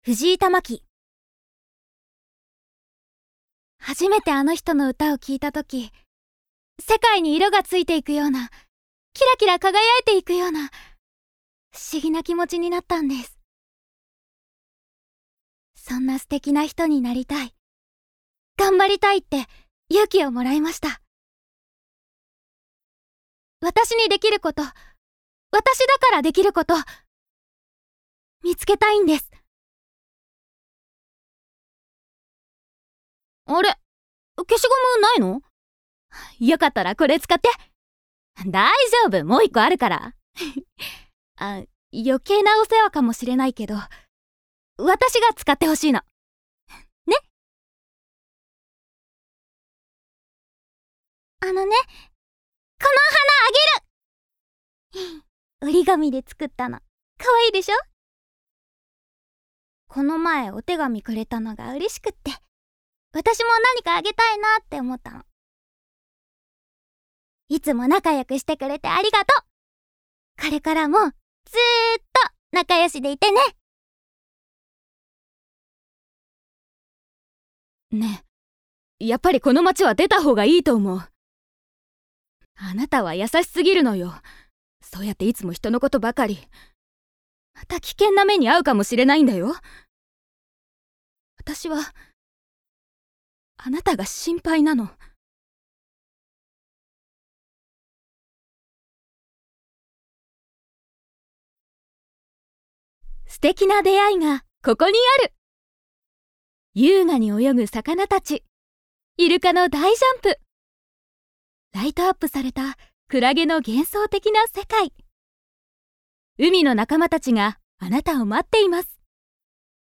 方言: 讃岐弁
VOICE SAMPLE